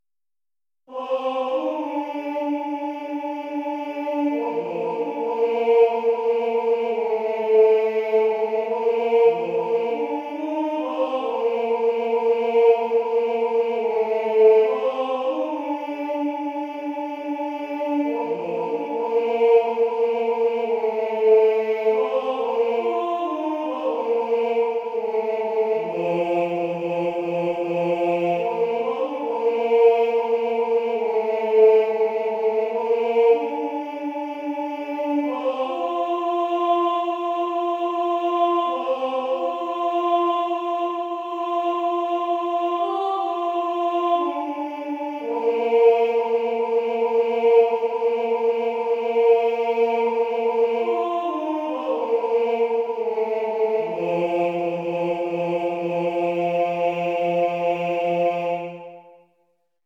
gotland_alt.mp3